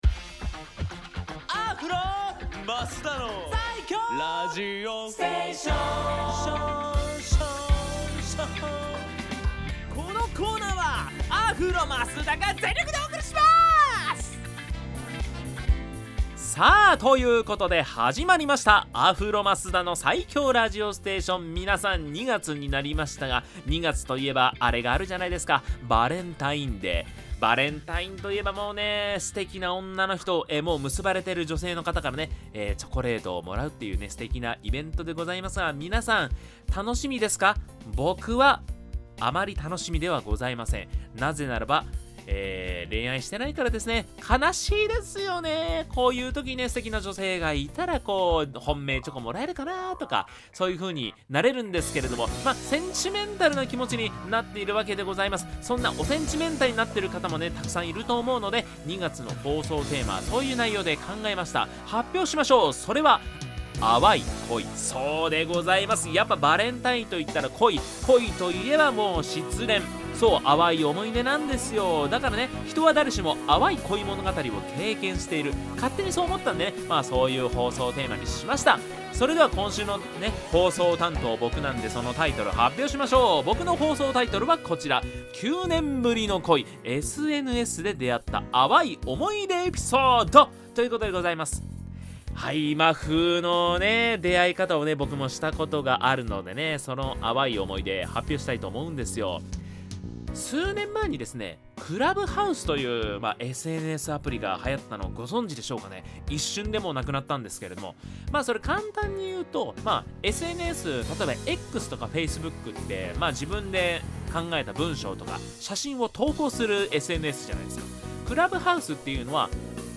こちらが放送音源です♪